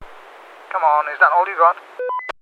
Tag: 战争 语音 战斗喷气机 语音 军事 飞行员 无线电 飞机 飞机 样品 要求 战斗 男性